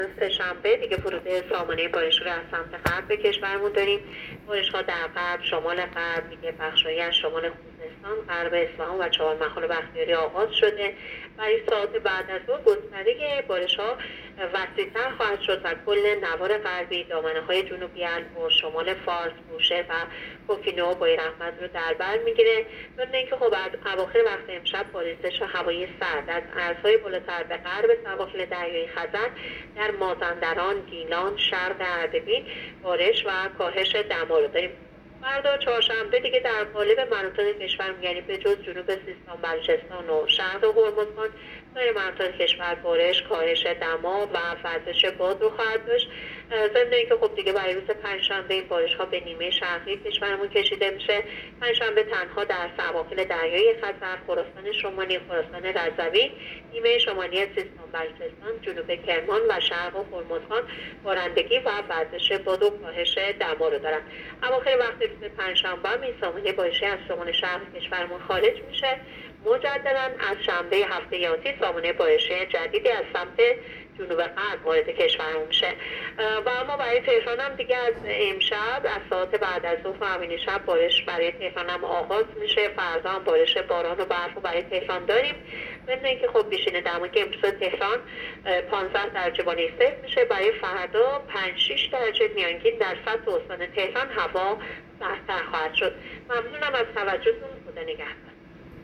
گزارش رادیو اینترنتی از آخرین وضعیت آب و هوای چهاردهم بهمن؛